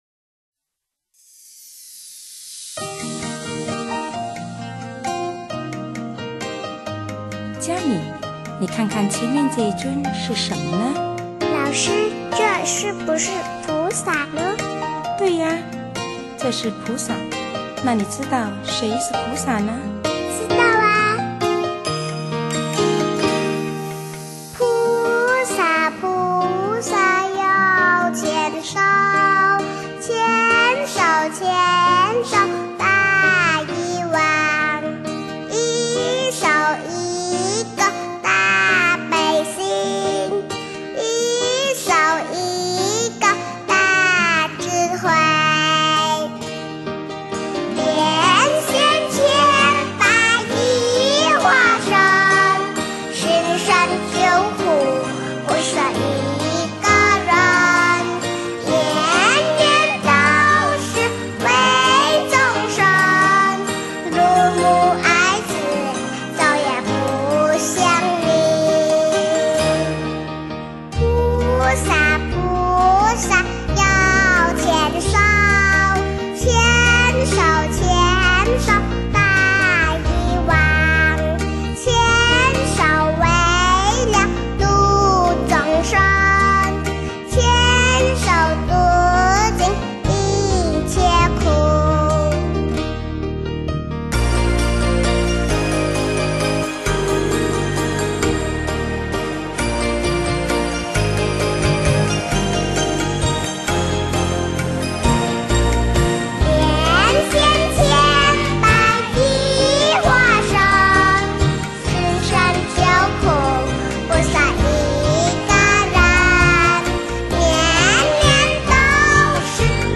娇嗔的鼻音、生嫩的捲舌、认真的唱和，天真童颜唱出了无邪歌声。讨喜的声音表情，有如一股清凉微风，舒畅了每个小孩大人的心。
让童声留住了童心，那是人类最纯真的心灵。